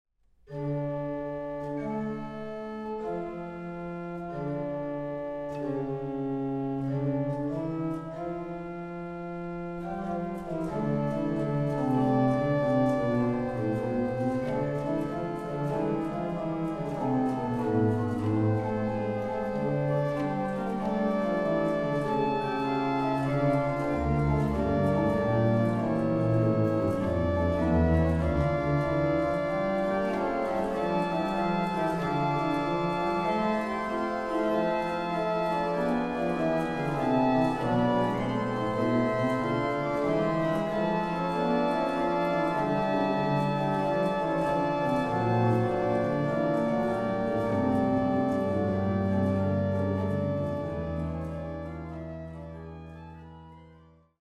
1755 erbaut für Prinzessin Anna Amalia von Preußen